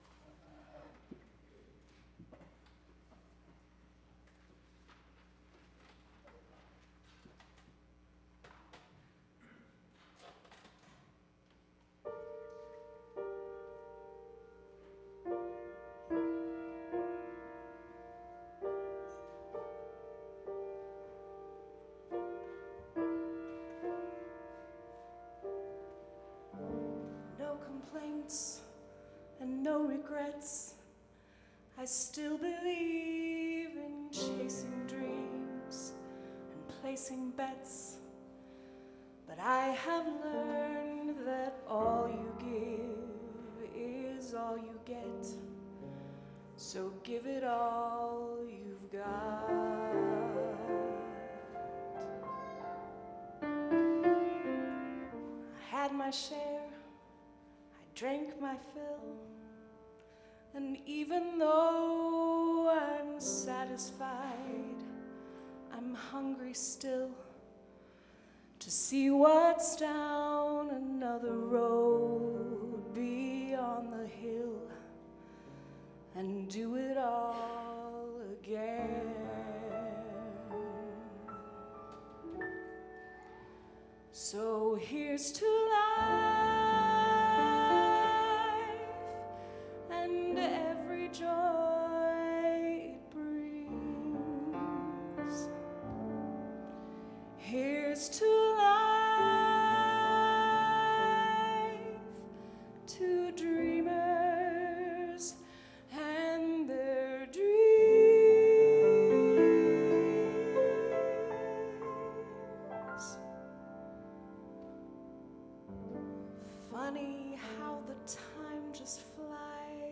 vocals.
piano.